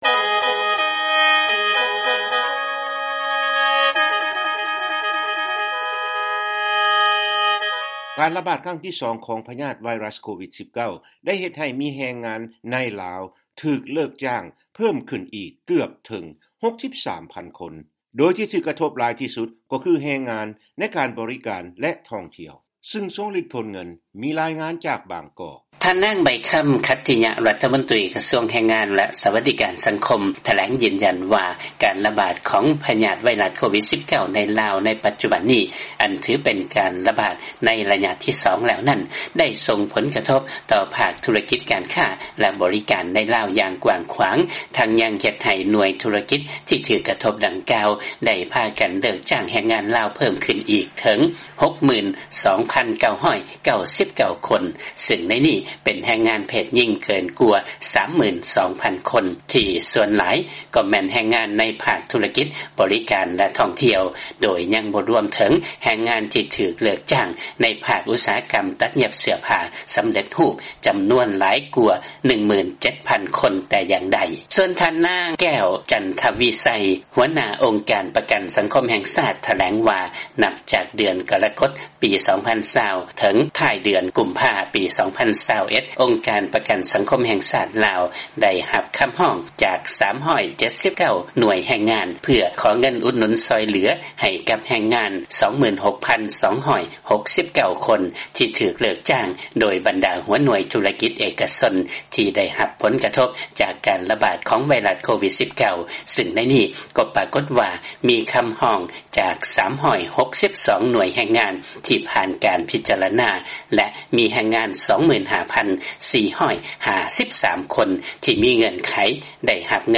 ຟັງລາຍງານ ການລະບາດຄັ້ງທີ 2 ຂອງ COVID-19 ໄດ້ເຮັດໃຫ້ມີ ແຮງງານໃນ ລາວ ຖືກເລີກຈ້າງເພີ່ມຂຶ້ນອີກ ເກືອບເຖິງ 63,000 ຄົນ